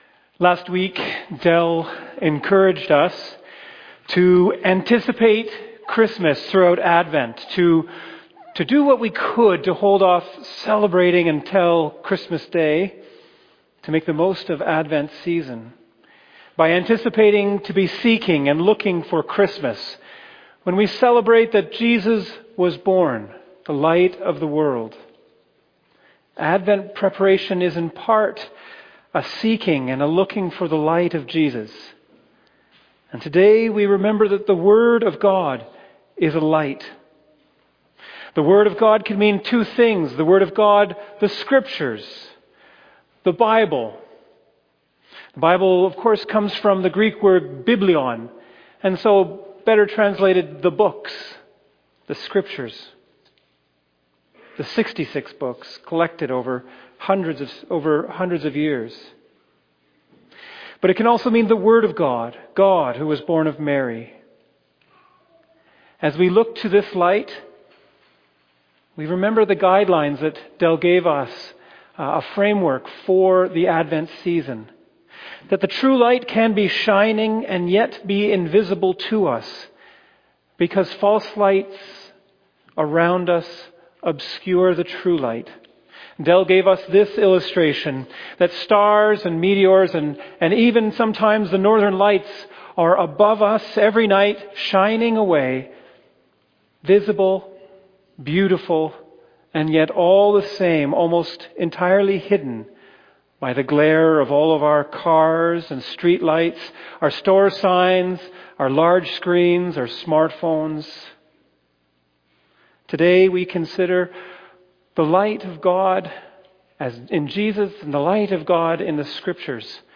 2025 Sermon December 7 2025
fbcsermon_2025_Dec7.mp3